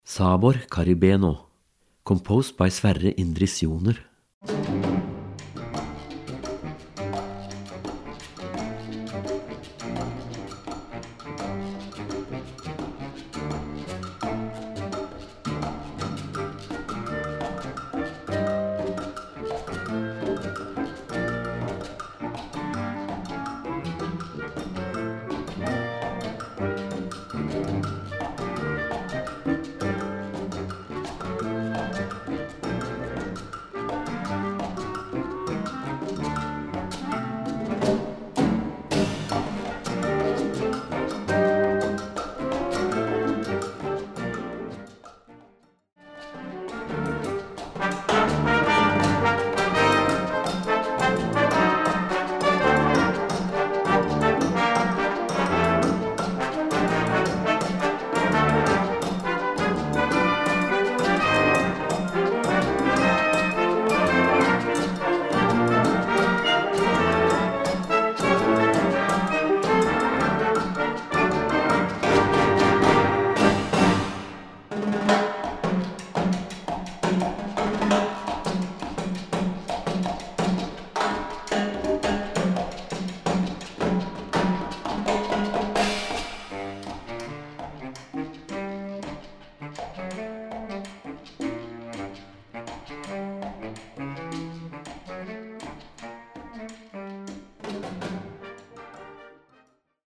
Gattung: für Blasorchester
Besetzung: Blasorchester